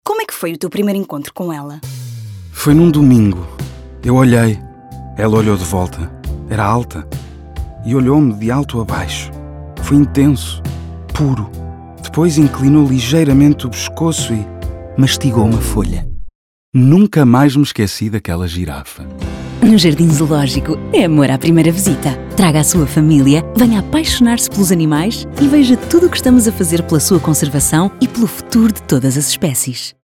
Spot de rádio 2